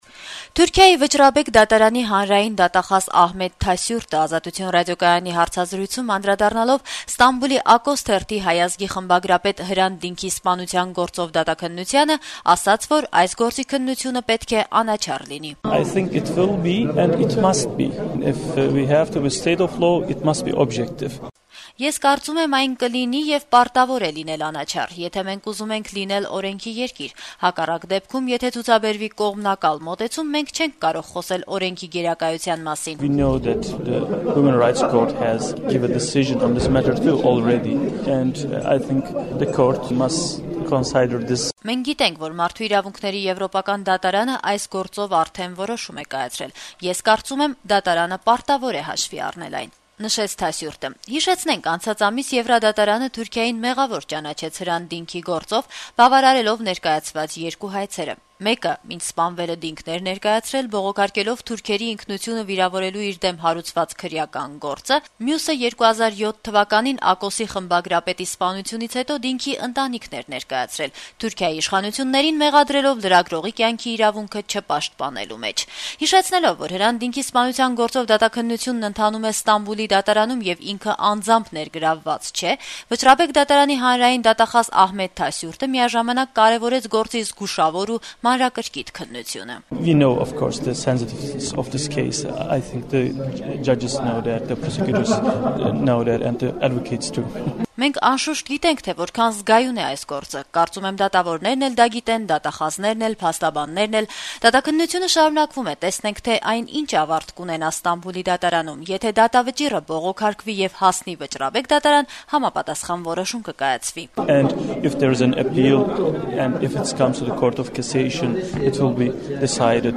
Հարցազրույց Թուրքիայի Վճռաբեկ դատարանի դատախազի հետ